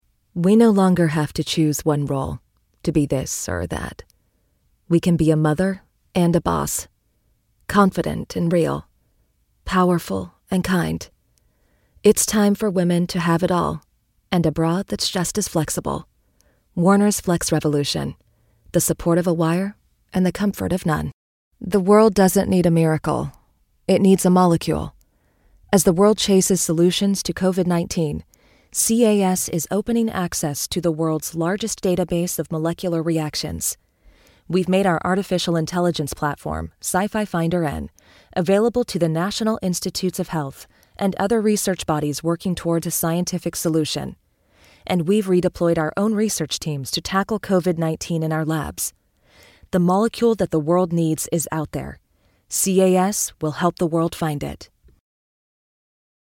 Demo
Adult, Young Adult
Has Own Studio